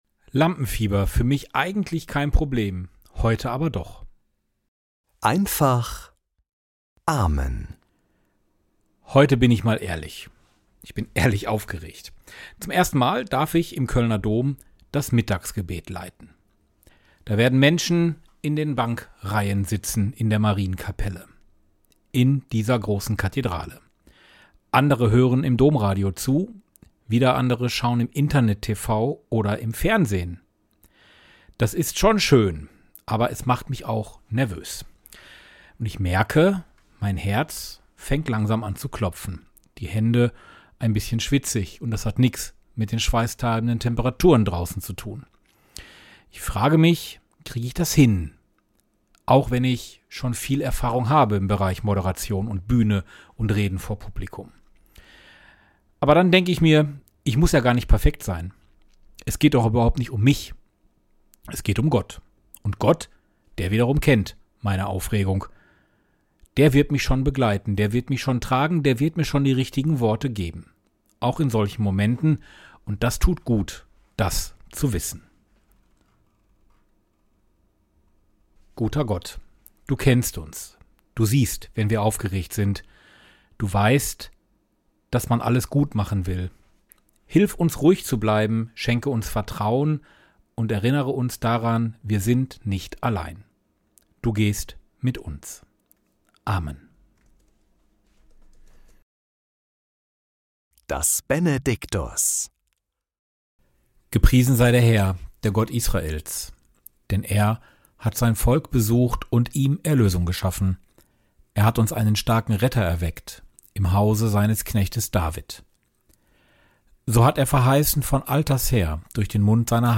Morgenimpuls in Einfacher Sprache